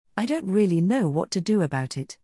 逆にイギリス英語では、高音から低音まで音色が大きく変化することがよくあります。
イギリス英語
BE-intonation.mp3